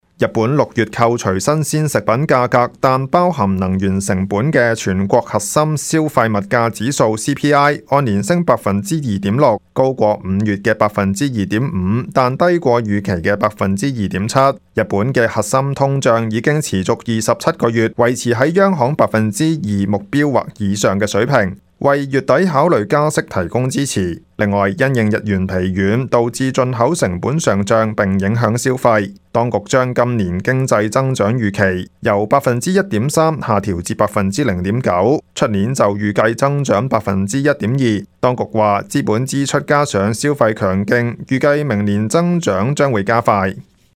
news_clip_19794.mp3